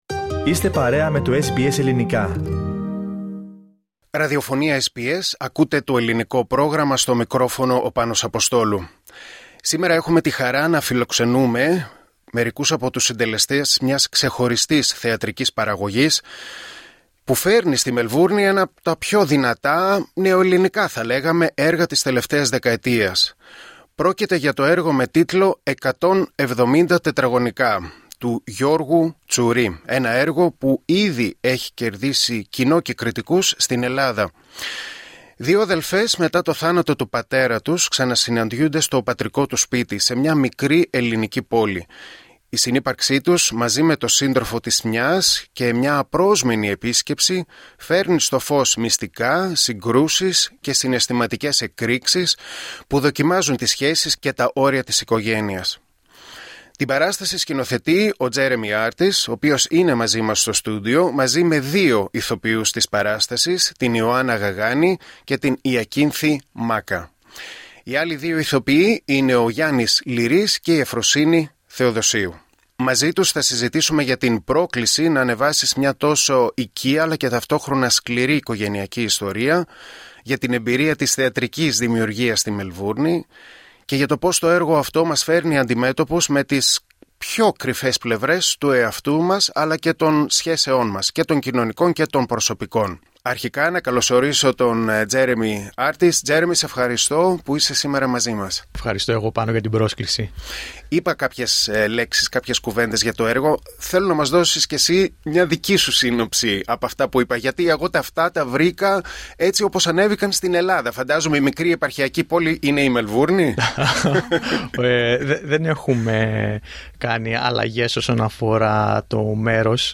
Στο SBS Greek μίλησαν οι συντελεστές, φωτίζοντας τις προκλήσεις και τις συγκινήσεις πίσω από την παράσταση.